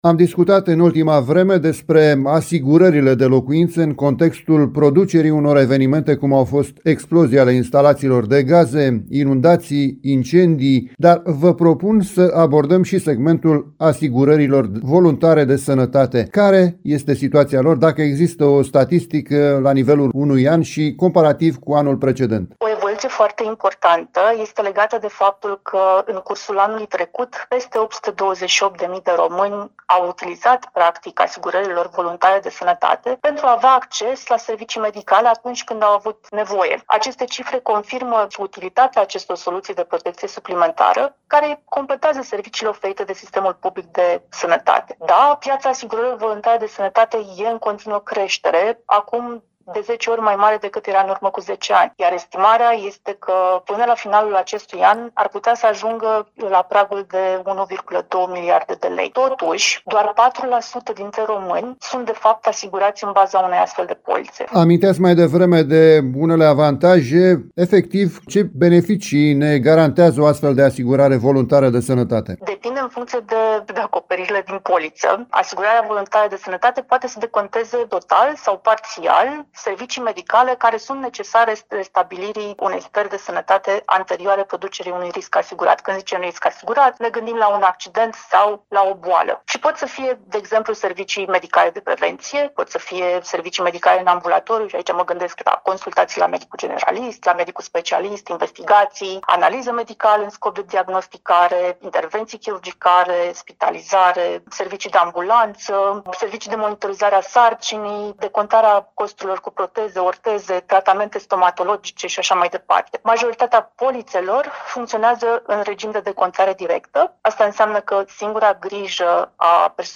specialist în asigurări de viață, în dialog cu